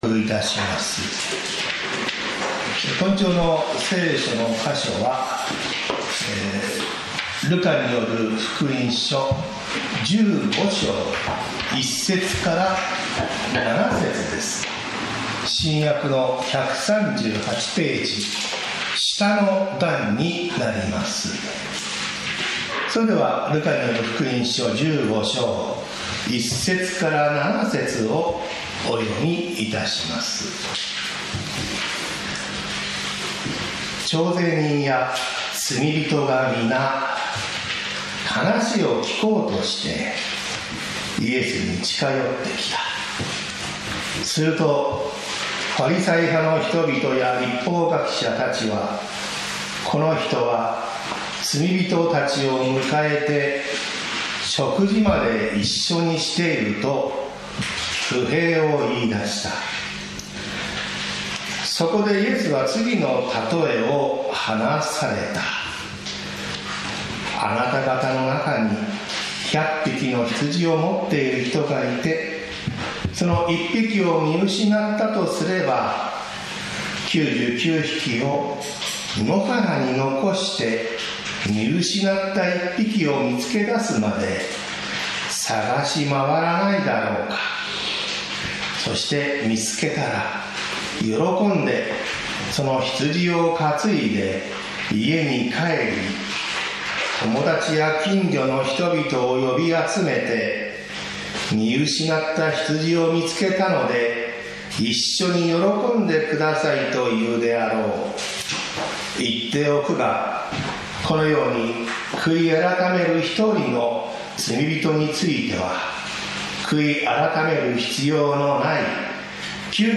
私たちは毎週日曜日10時30分から11時45分まで、神様に祈りと感謝をささげる礼拝を開いています。
日曜 朝の礼拝